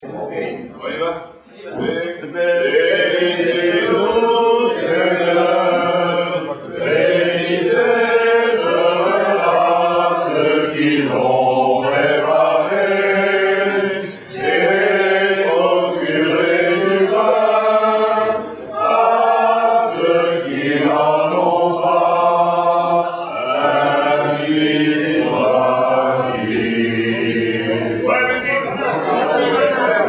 Fête des Anciens du 25 avril 2004
Benedicite avant le déjeuner aiff (120 ko)